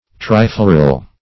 Search Result for " trifloral" : The Collaborative International Dictionary of English v.0.48: Trifloral \Tri*flo"ral\, Triflorous \Tri*flo"rous\, a. [Pref. tri- + L. flos, floris, flower.]